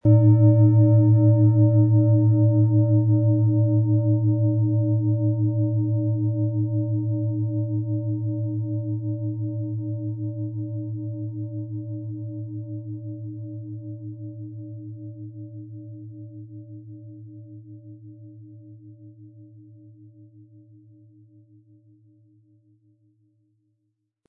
Planetenschale® Gelassen und Beruhigt sein & Meditationen mit beruhigten Gedanken mit Thetawellen, Ø 21,2 cm inkl. Klöppel
Im Sound-Player - Jetzt reinhören können Sie den Original-Ton genau dieser Schale anhören.
Durch die traditionsreiche Fertigung hat die Schale vielmehr diesen kraftvollen Ton und das tiefe, innere Berühren der traditionellen Handarbeit
Der gratis Klöppel lässt die Schale wohltuend erklingen.
MaterialBronze